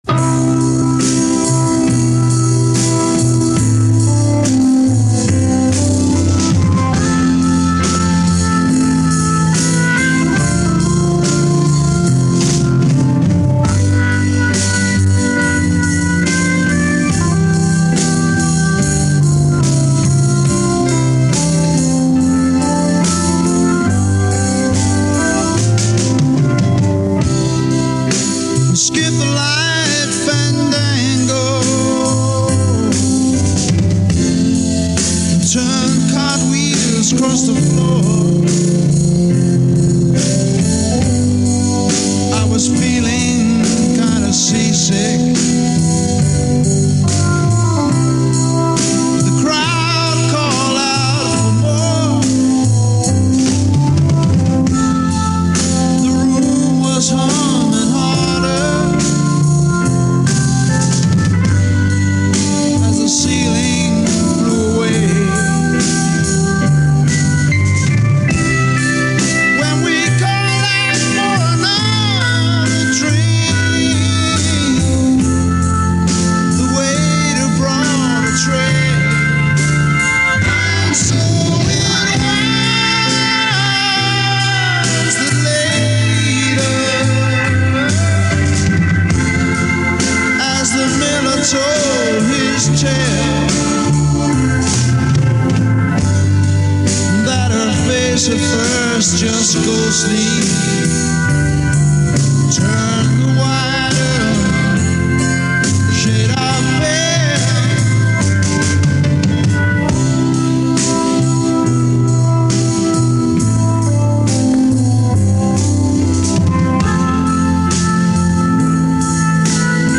Recorded between June and September 1967 - BBC Radio